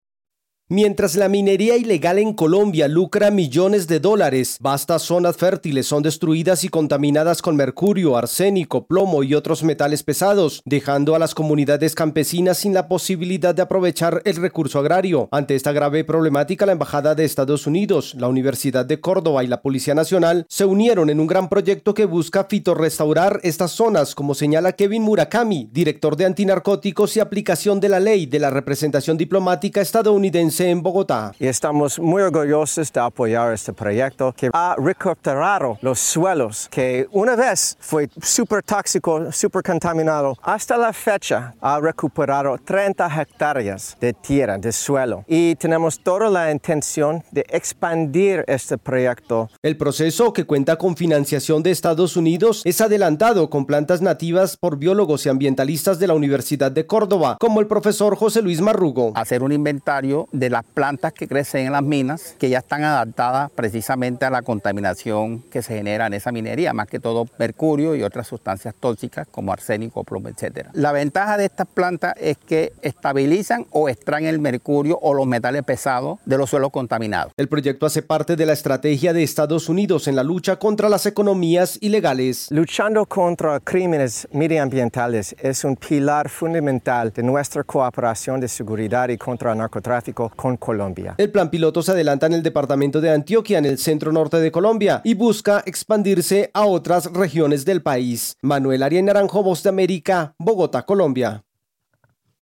Con el apoyo financiero y logístico de la embajada de Estados Unidos, avanza en Colombia un plan piloto para recuperar tierras contaminadas con mercurio en la minería ilegal. Desde Colombia informa el corresponsal de la Voz de América